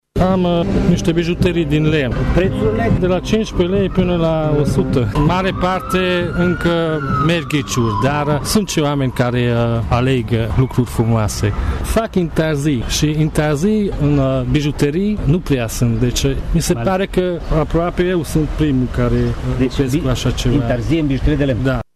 În Piața Teatrului din Tg.Mureș s-a organizat un mini-târg al meșteșugarilor.